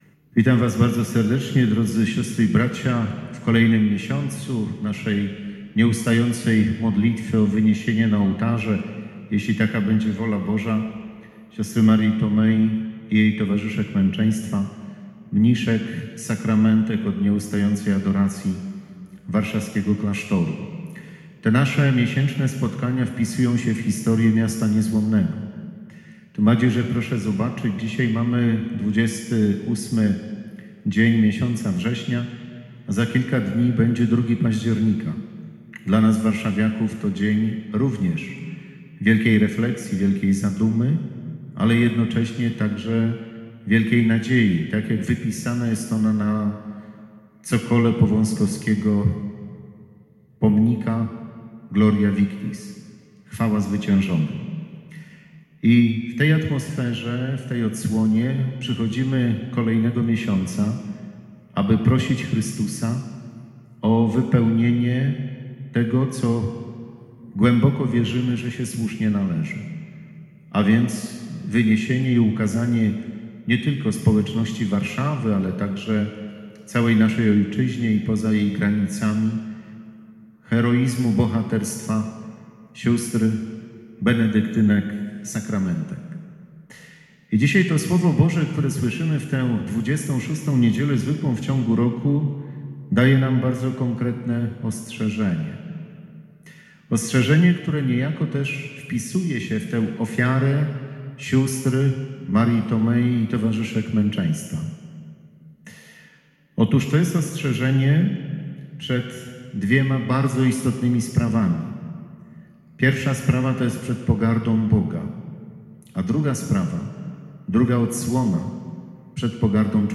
Msza św. o wyniesienie na ołtarze s. Marii Tomei i towarzyszek męczeństwa
Homilia